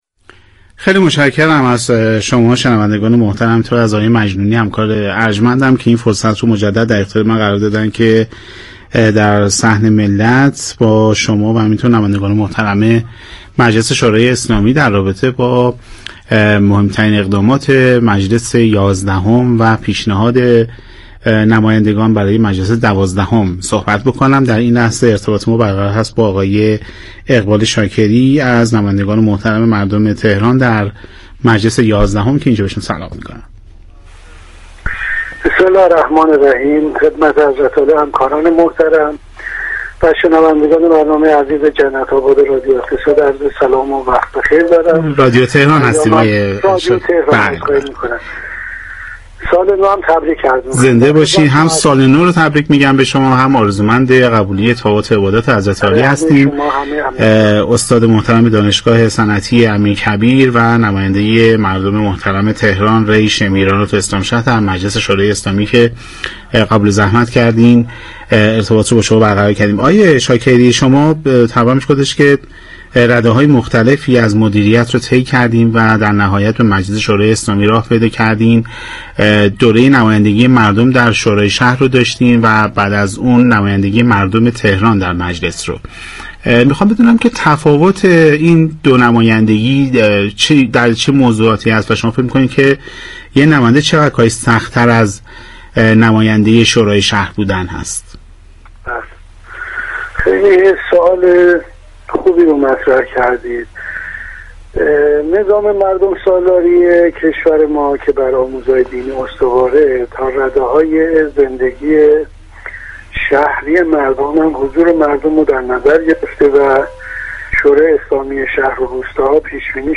به گزارش پایگاه اطلاع رسانی رادیو تهران، اقبال شاكری نماینده مردم تهران در دور یازدهم مجلس شواری اسلامی در گفت و گو با ویژه برنامه «جنت آباد» اظهار داشت: حیطه وظایف شورای اسلامی شهر و روستا و مجلس شواری اسلامی با هم متفاوت است.